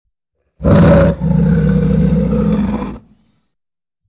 Lion Roaring Sound Effect -no Copyright Botão de Som